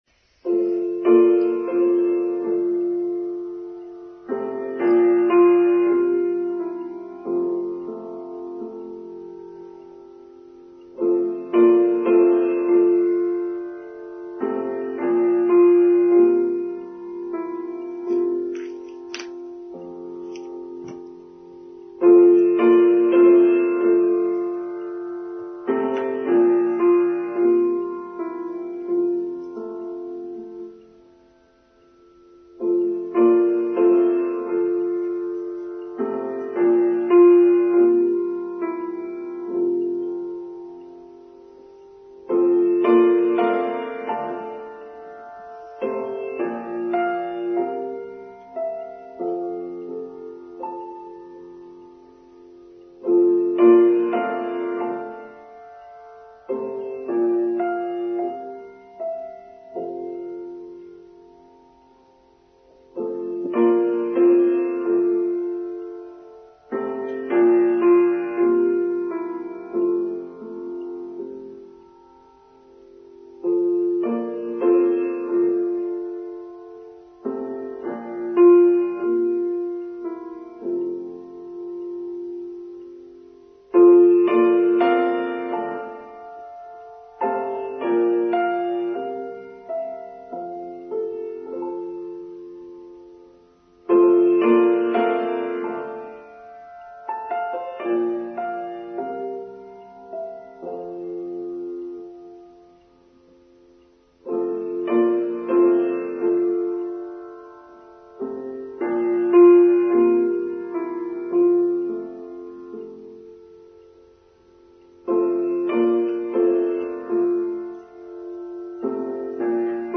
The Power of Dreams: Online Service for Sunday 29th October 2023